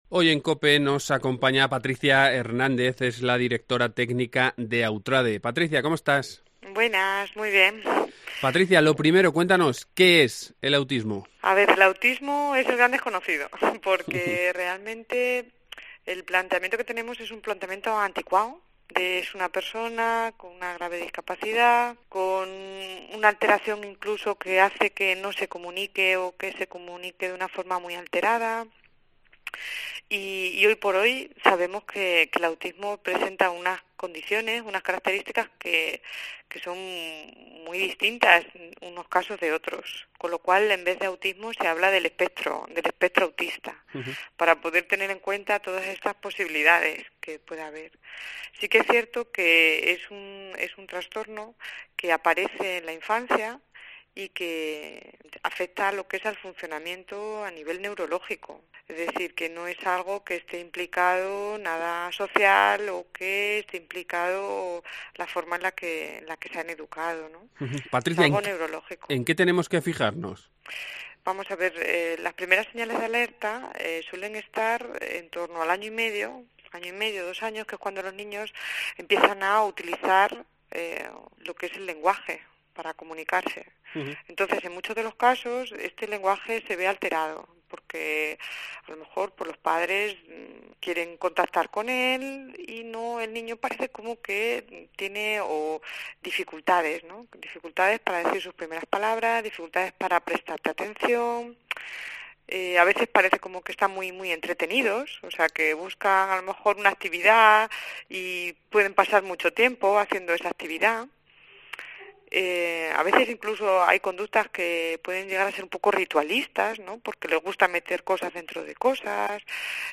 Charlamos con